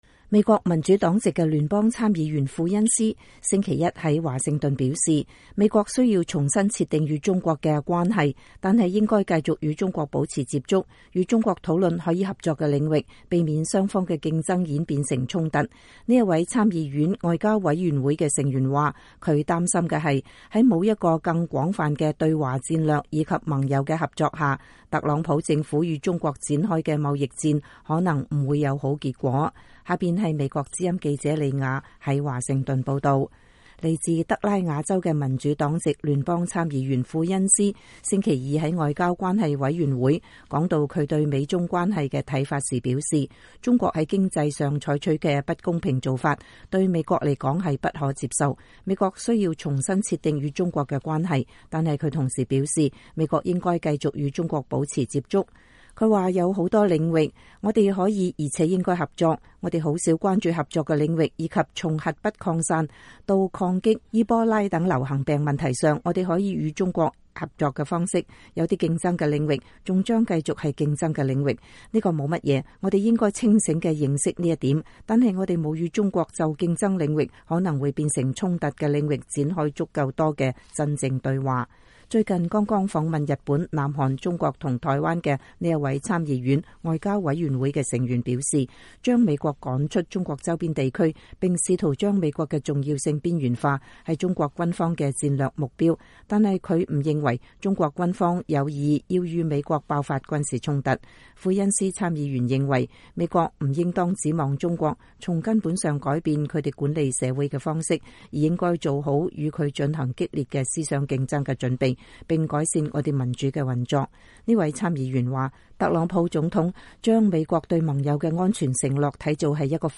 民主黨籍參議員庫恩斯談美中關係。